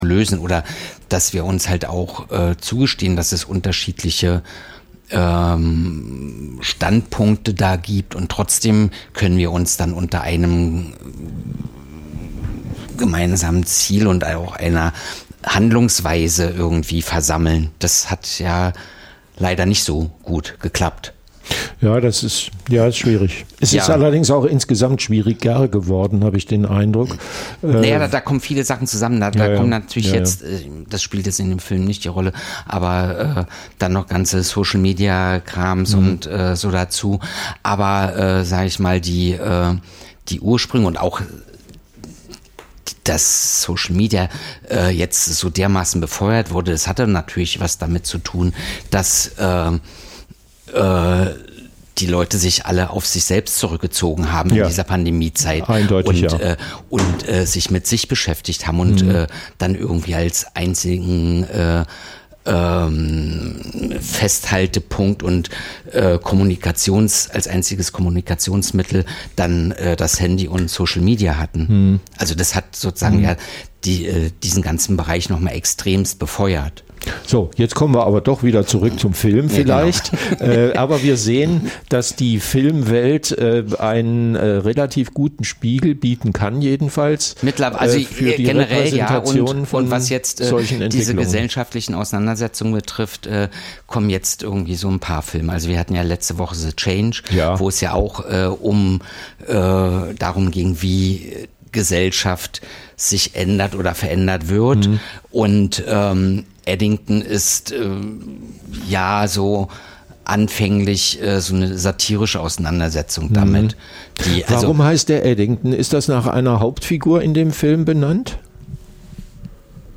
Das tagesaktuelle Livemagazin sendet ab 2024 montags bis freitags 9-11 Uhr.